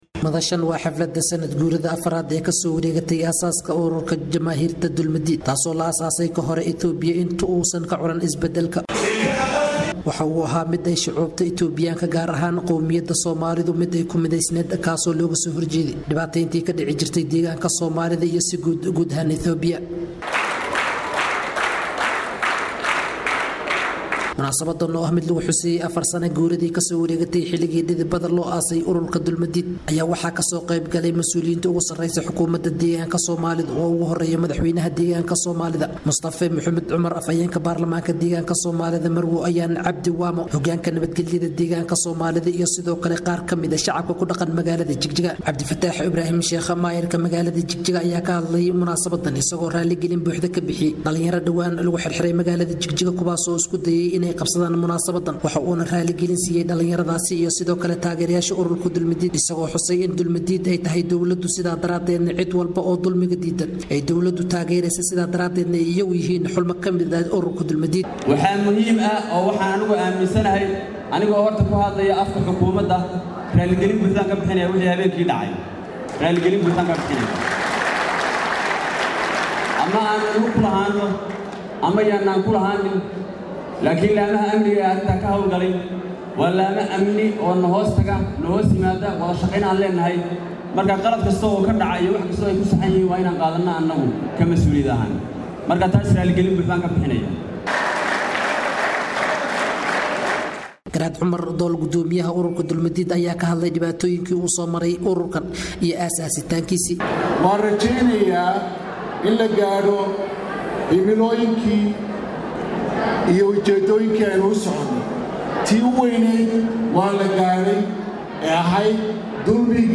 Caalamka DHAGEYSO:Warbixin:Siduu kusoo billowday kacdoonkii deegaanka Soomaalida ee xilka looga tuuray xukumaddii Cabdi Maxamuud Cumar (Cabdi Iley)?